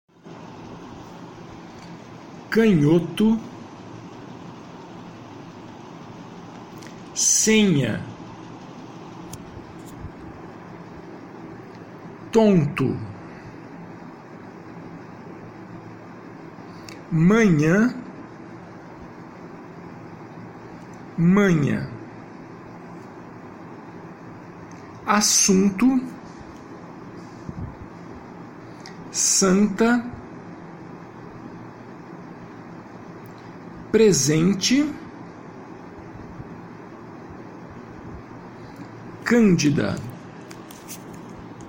Faça download dos arquivos de áudio e ouça a pronúncia das palavras a seguir para transcrevê-las foneticamente.
GRUPO 2 - Nasais - Arquivo de áudio -->